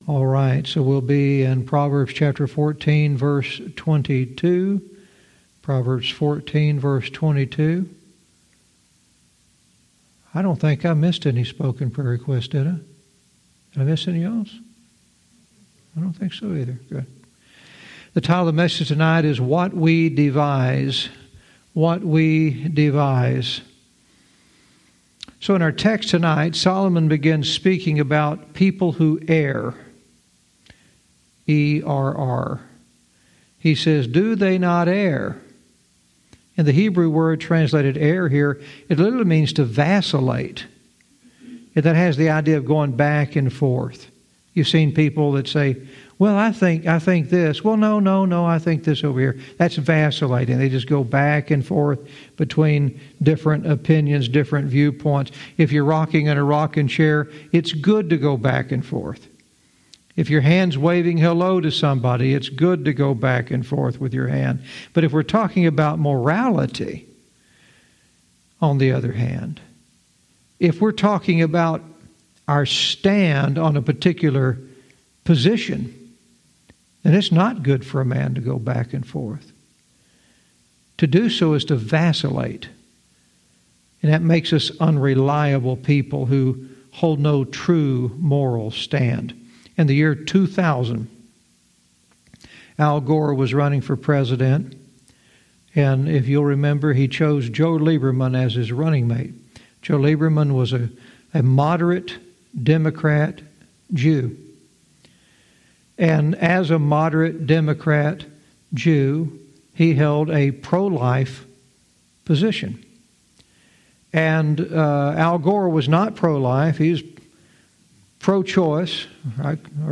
Verse by verse teaching - Proverbs 14:22 "What We Devise"